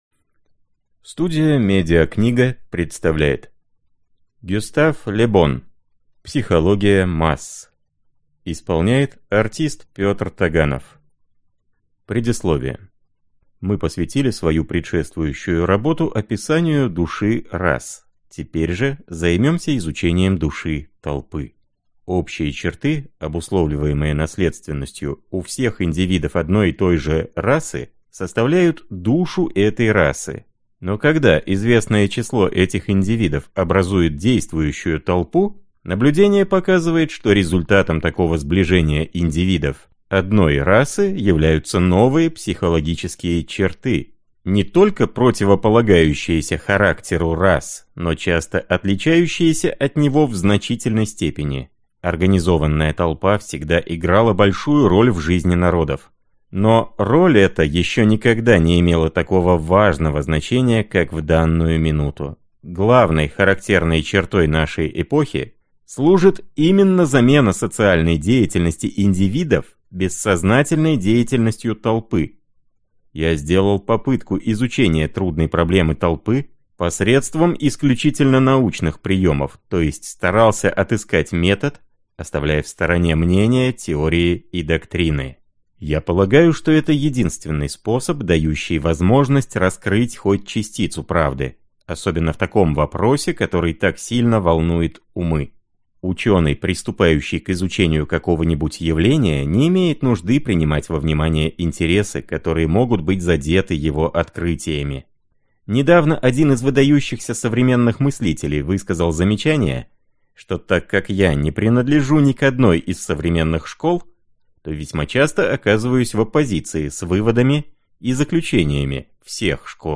Аудиокнига Психология масс | Библиотека аудиокниг
Прослушать и бесплатно скачать фрагмент аудиокниги